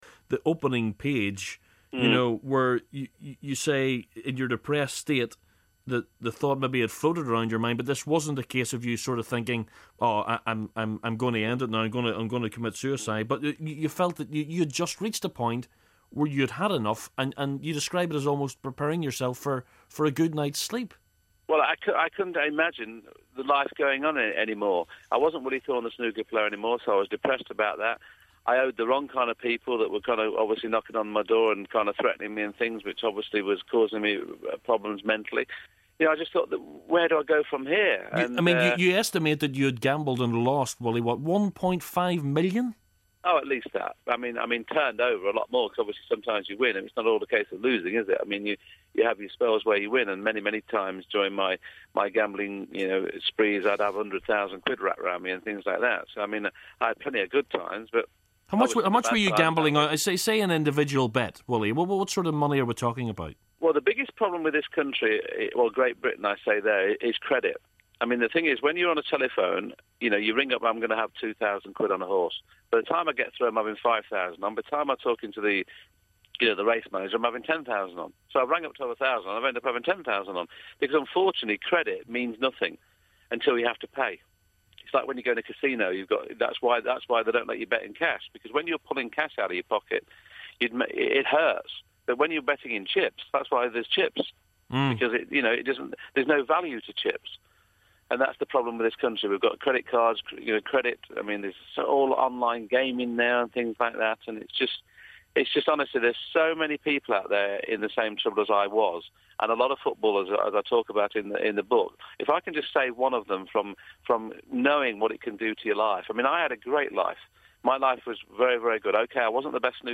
Big Friday Interview Mr Maximum himself Willie Thorne.